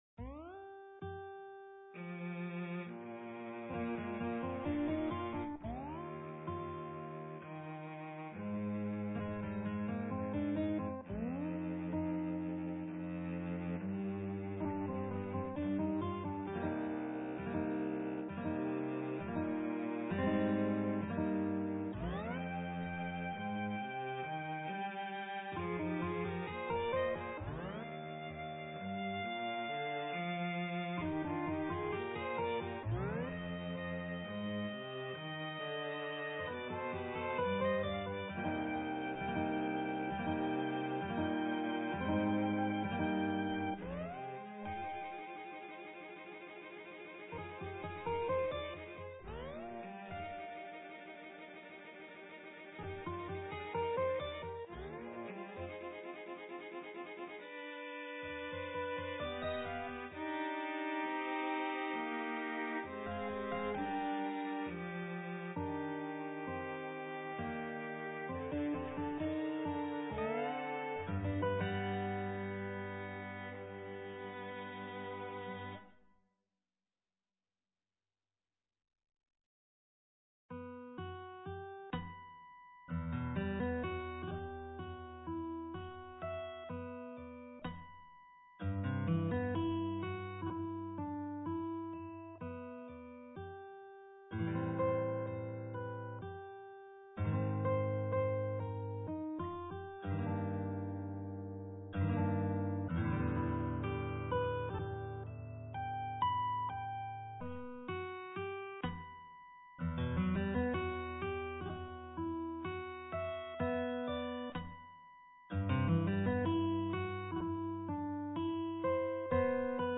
for guitar, violin and violoncello
three romantic pieces with a Mediterranean atmosphere